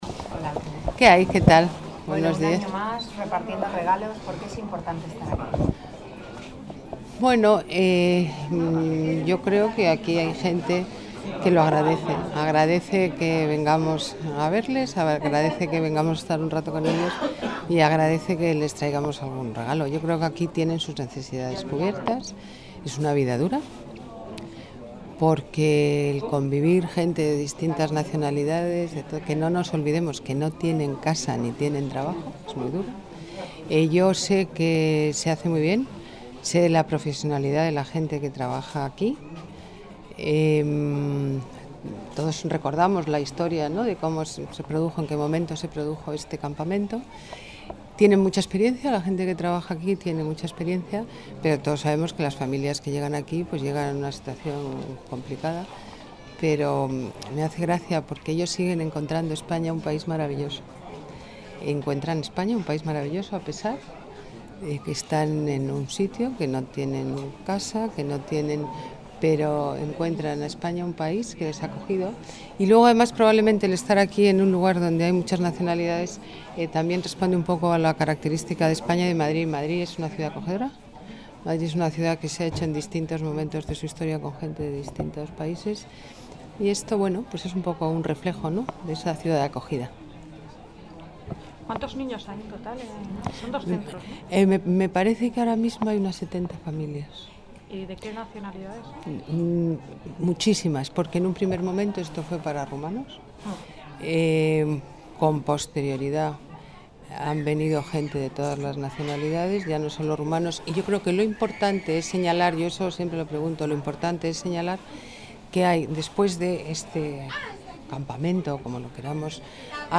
Nueva ventana:Declaraciones de la alcaldesa, Ana Botella